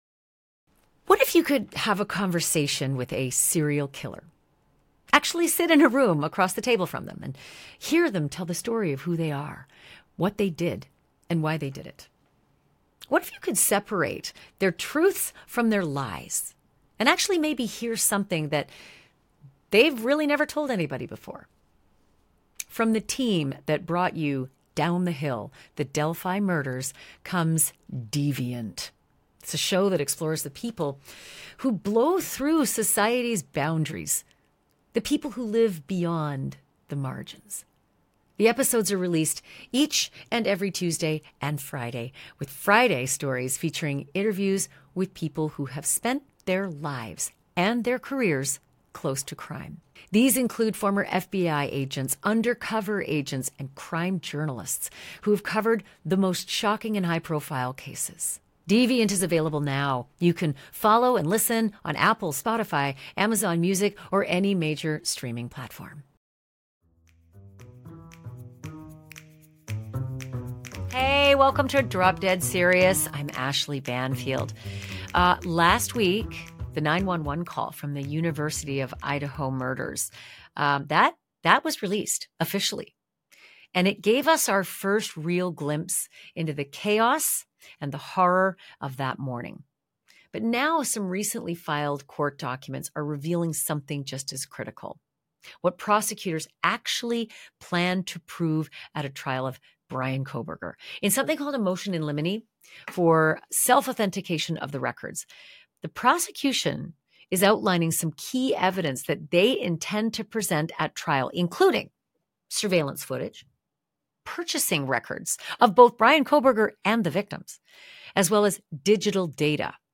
In this episode of Drop Dead Serious, Ashleigh sits down with NewsNation’s Brian Entin to break down new court filings that reveal some of what prosecutors plan to prove at trial.